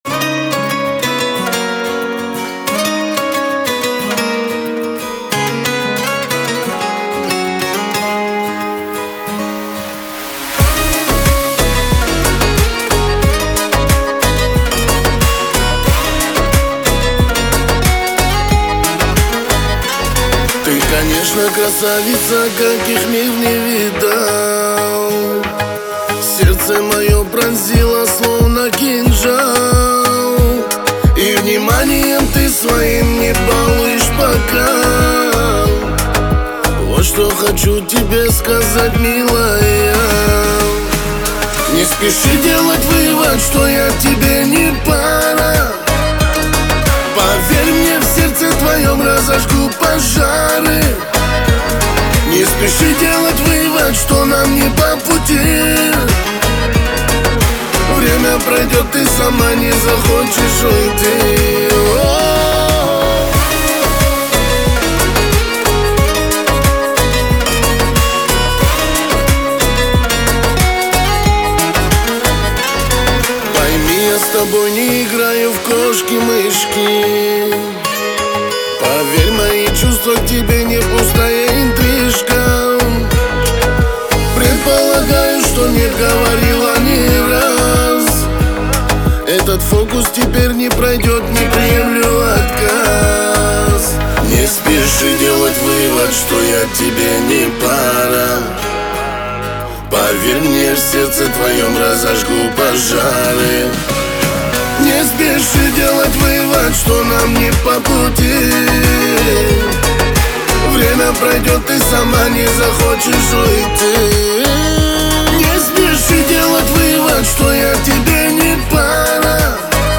Качество: 320 kbps, stereo
Кавказская музыка